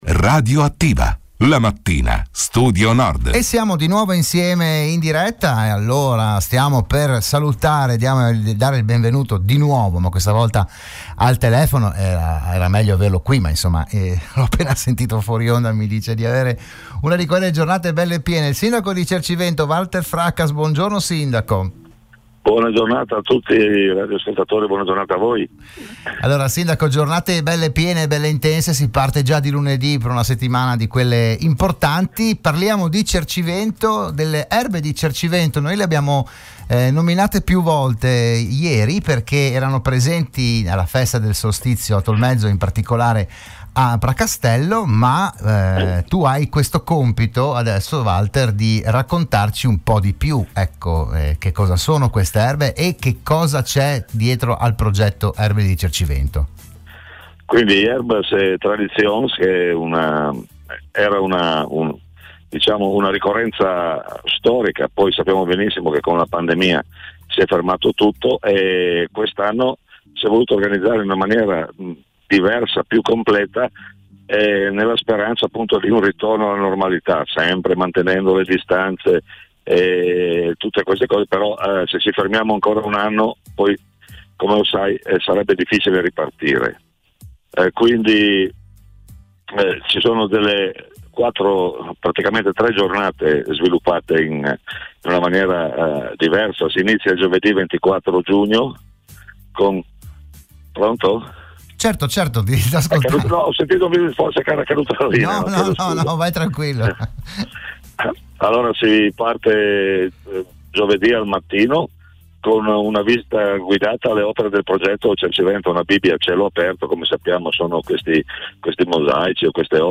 L'audio dell'intervento a Radio Studio Nord del sindaco Valter Fracas